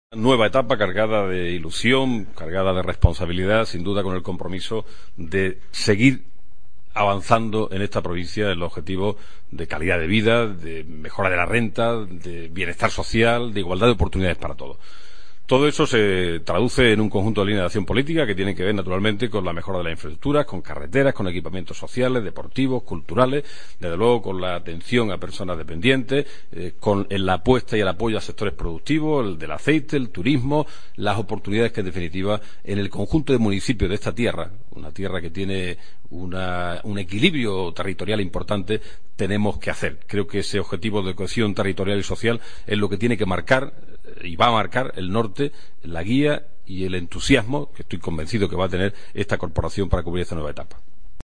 Felipez López, cuando era presidente de la Diputación en un audio de archivo de 2005